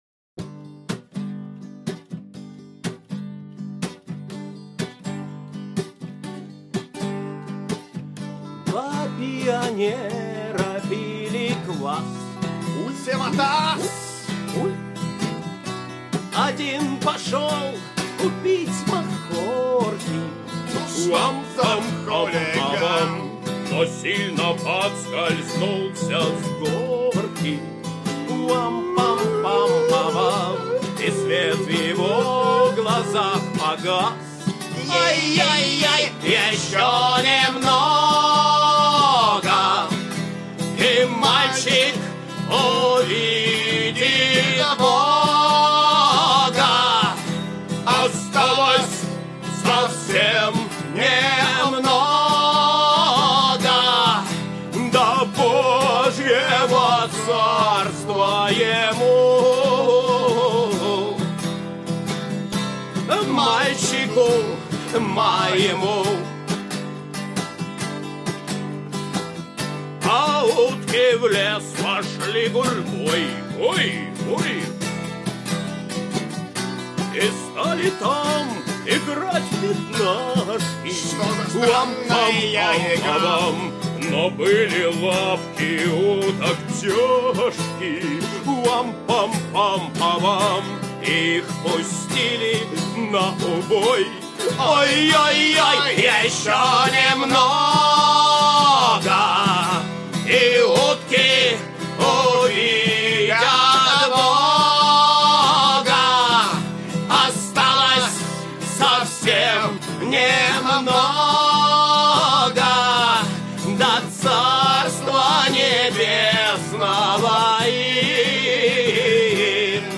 Музыкальный хостинг: /Панк